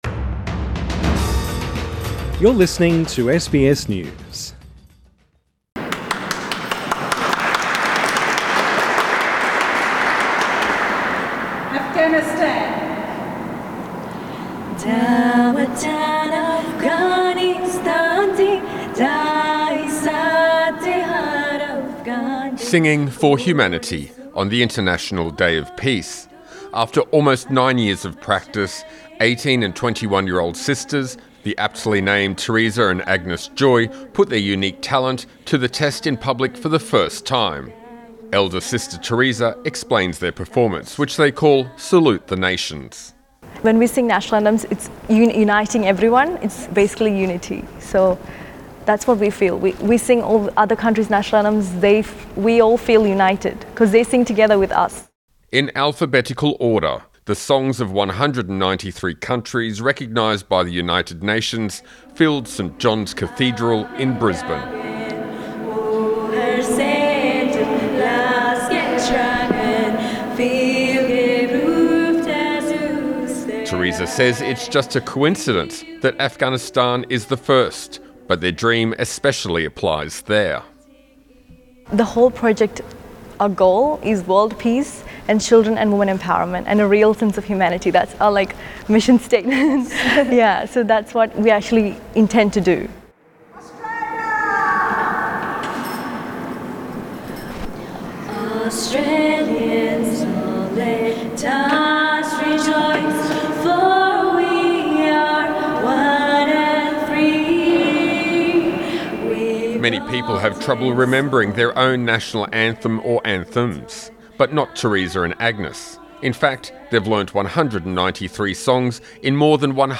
A new world record: Australian women sing 193 countries' national anthems in language